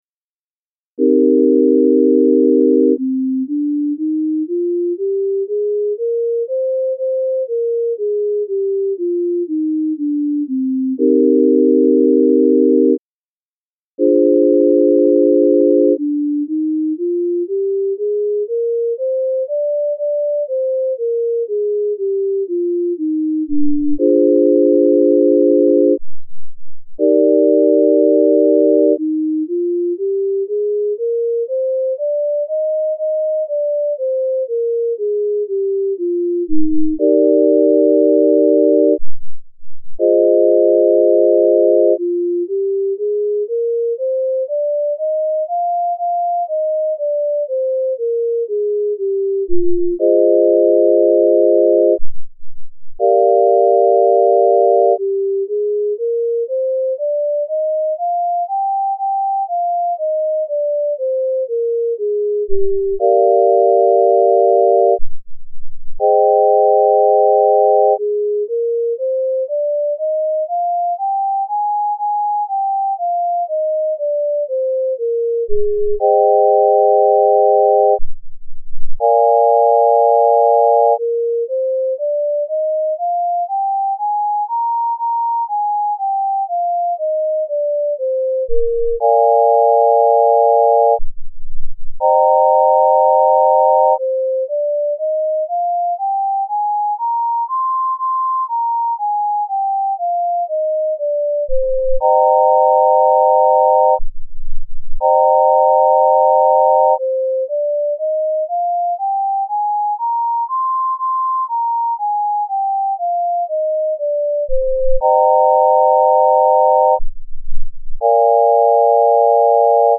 C-Minor Scale Using the Tempered Scale
music02_014_Minor_Scale_C_Temp.mp3